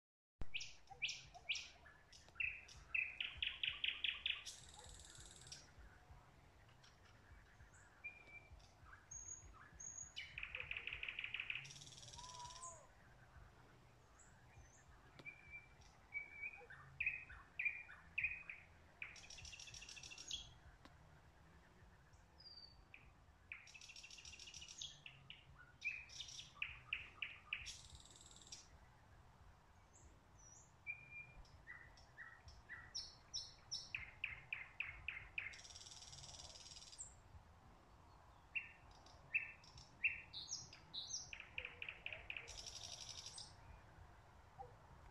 Birds -> Thrushes ->
Thrush Nightingale, Luscinia luscinia
StatusVoice, calls heard